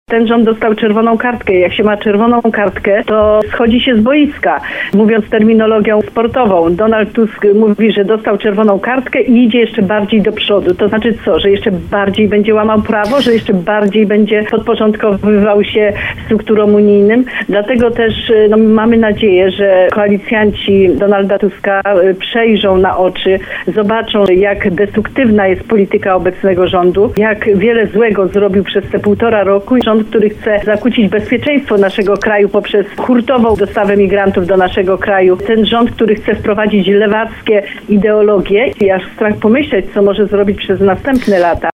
Nie można pozwolić na dalszą destrukcję państwa – tak poseł Prawa i Sprawiedliwości Józefa Szczurek-Żelazko skomentowała pomysł prezesa PiS Jarosława Kaczyńskiego, który chce utworzenia rządu technicznego.